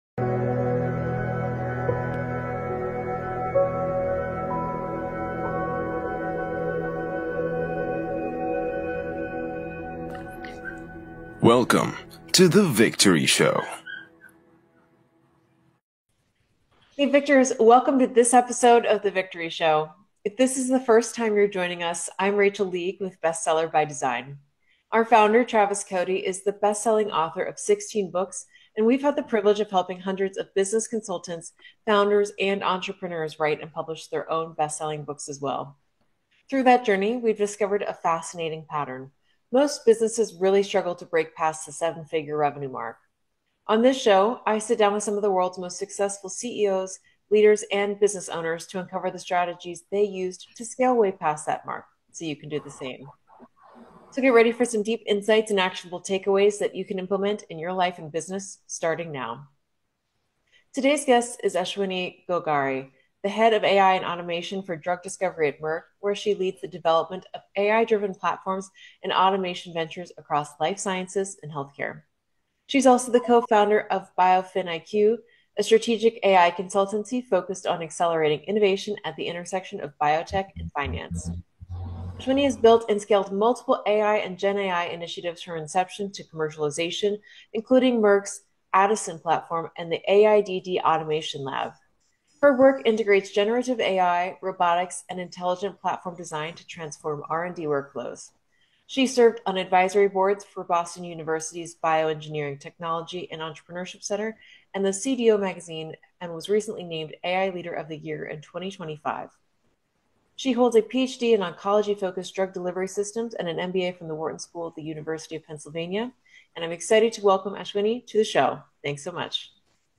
Disclaimer: This interview was conducted in August 2025.